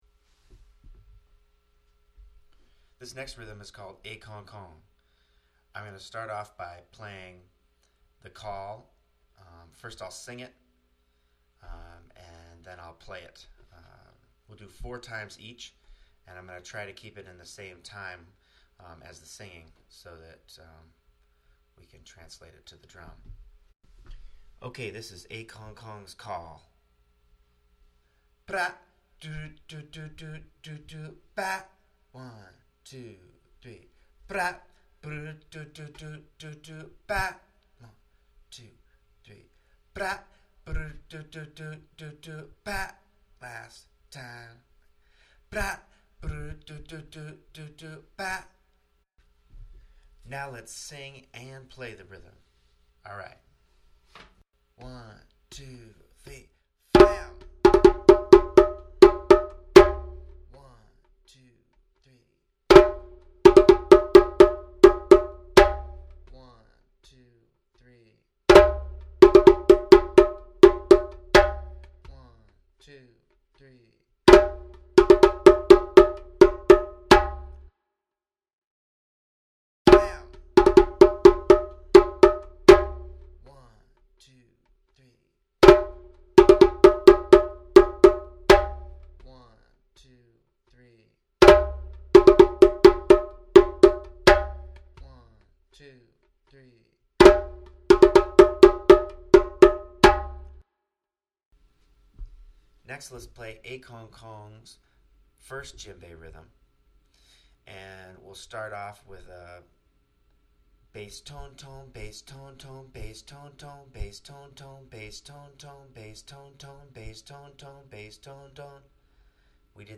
Call & rhythms 1 & 2 (6:06,5.9MB)